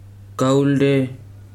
[káúldé] n. lime